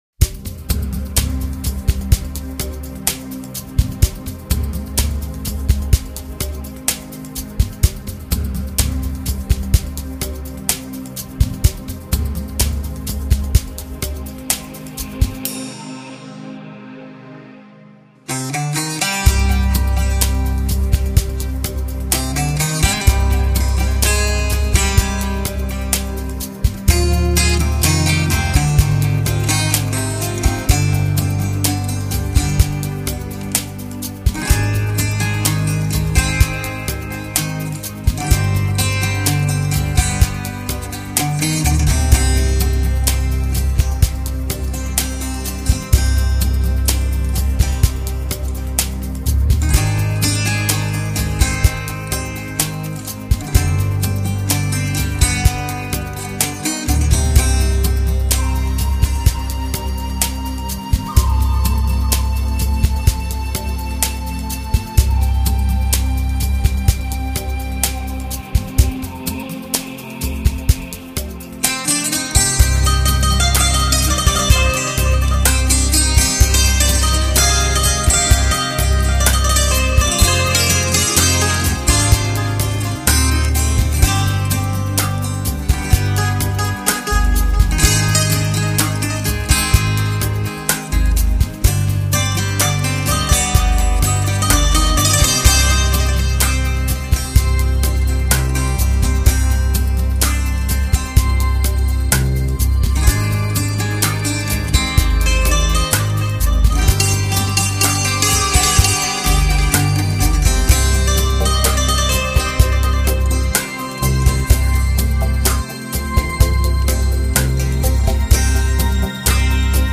大自然好像一首曲，一首无边无际的曲，每个音符都带有动听的音律，每个音节都带着欢快的节奏，每个音段都带有柔美和安适，